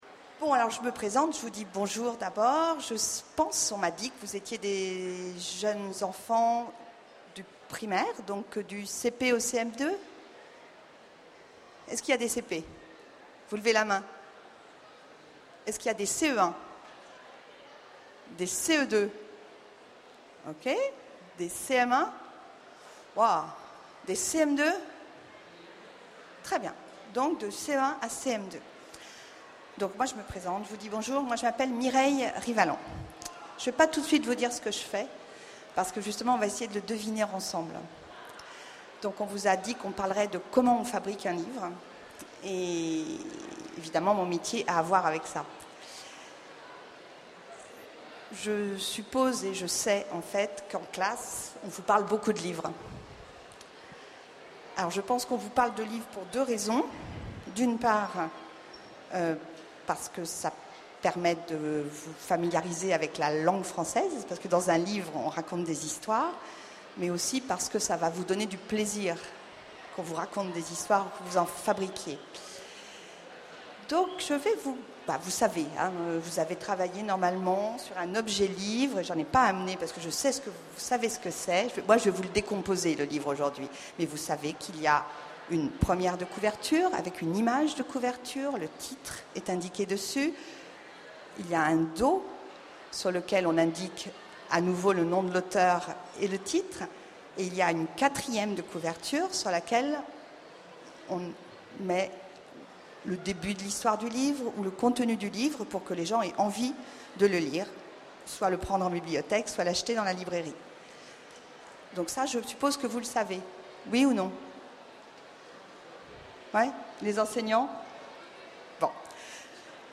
Utopiales 12 : Conférence Rencontre avec les éditions L'Atalante